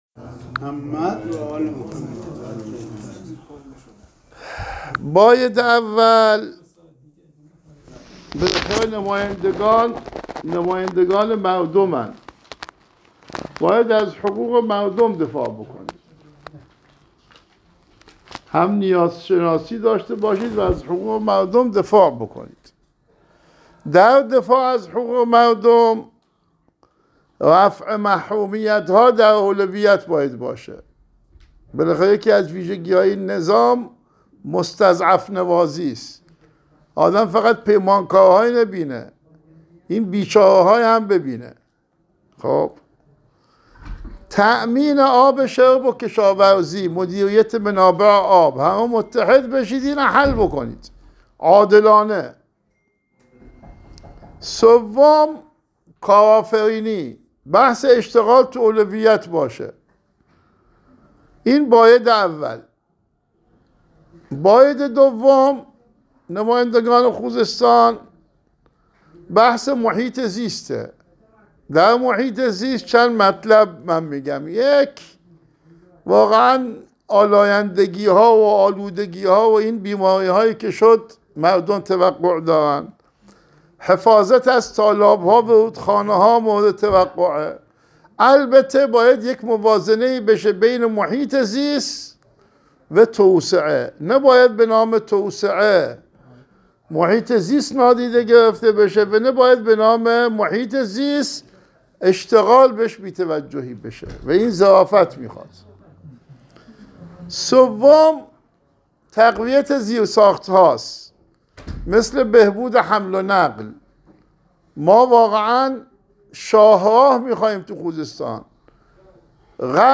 اهواز مرکز علمی فرهنگی امام حسین علیه السلام
در دیدار با جمعی از نمایندگان خوزستانی مجلس شورای اسلامی